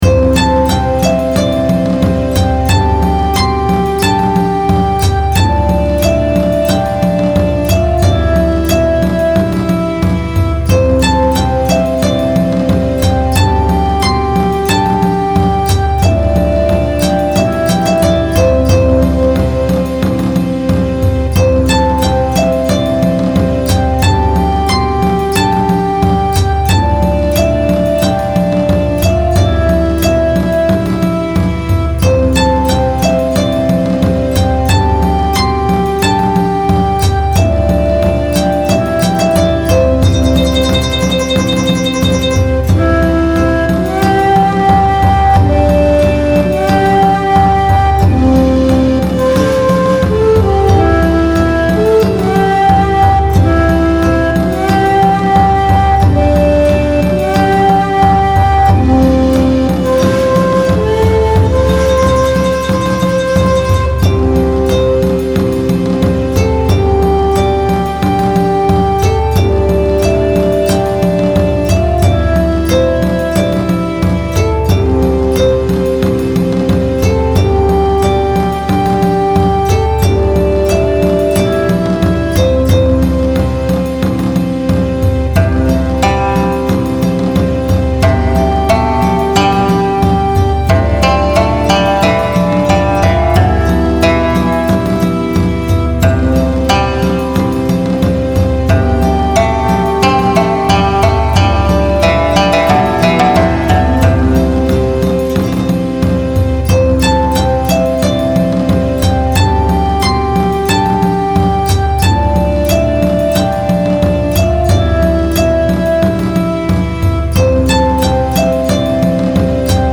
広い温泉旅館をイメージした和風のBGMです。複数使った太鼓が特徴的です。